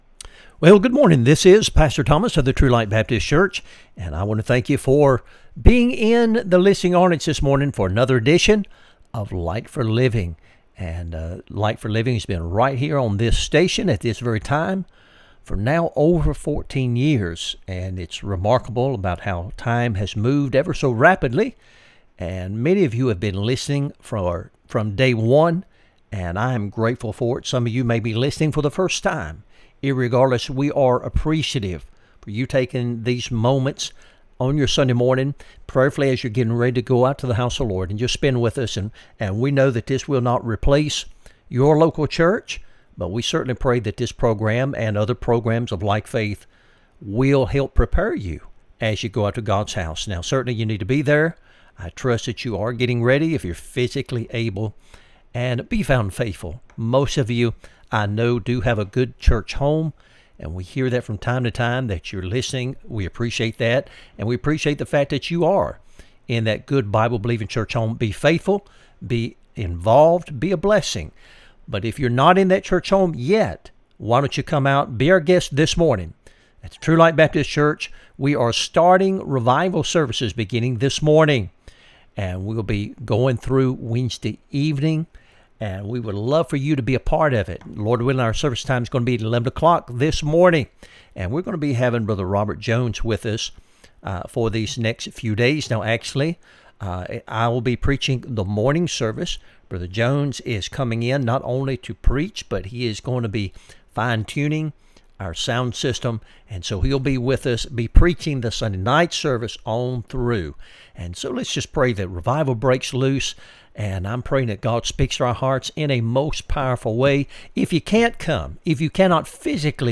Sermons | True Light Baptist Church of Alton, Virginia
Light for Living Radio Broadcast